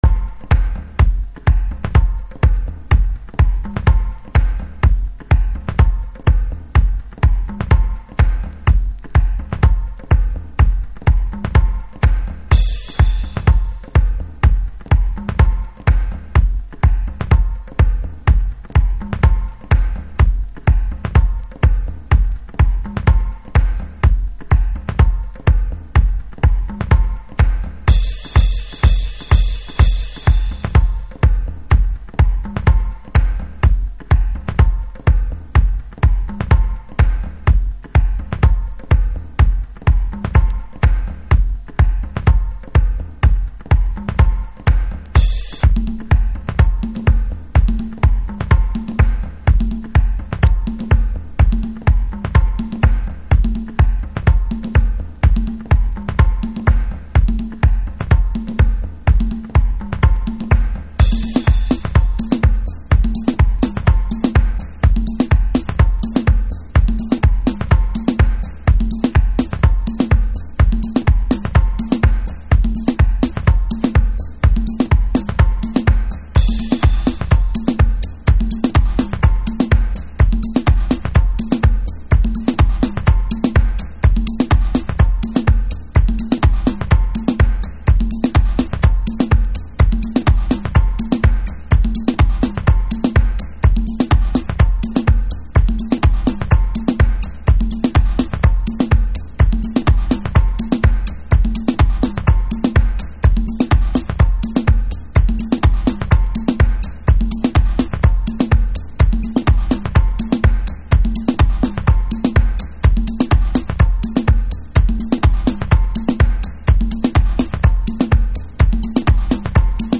中低域のグルーヴとアコースティックが混然一体となってグルーヴするのがUS DEEPの美鳴。
Chicago Oldschool / CDH